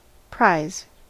Ääntäminen
US
IPA : /pɹaɪz/